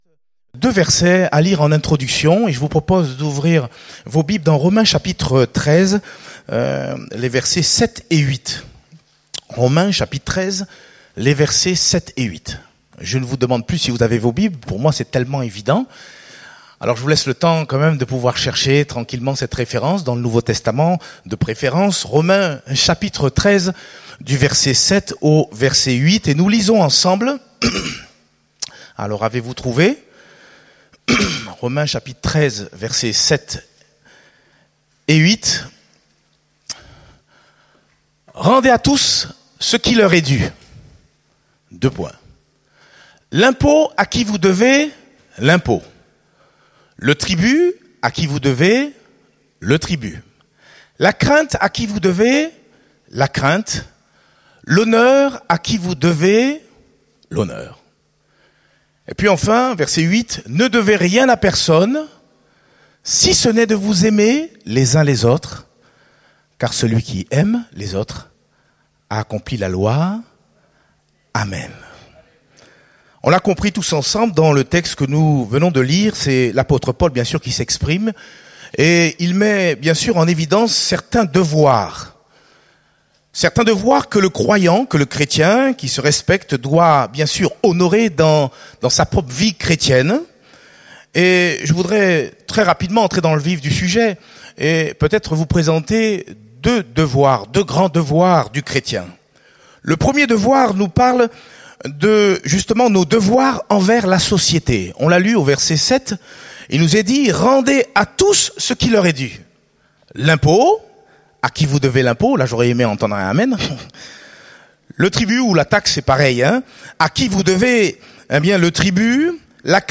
Date : 30 juin 2019 (Culte Dominical)